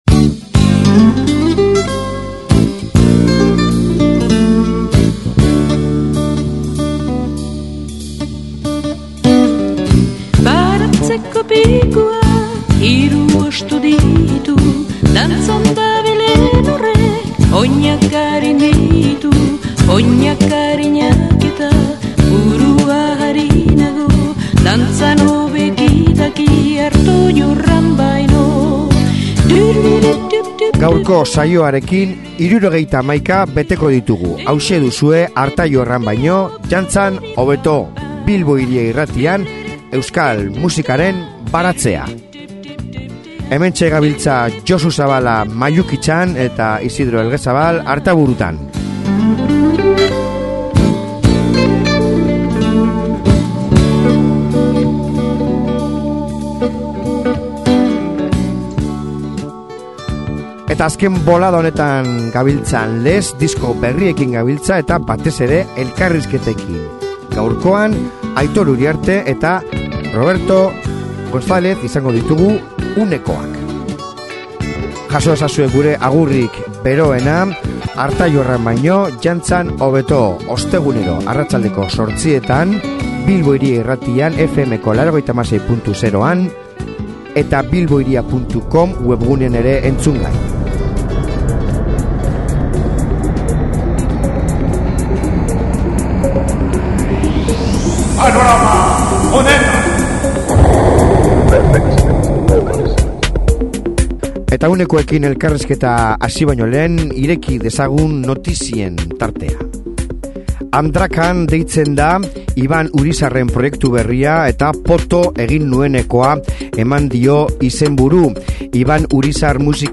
euren rock doinuak hustu eta saxo, piano eta rhodes zertzeladekin jantzi dituzte
izan ditugu gure estudioan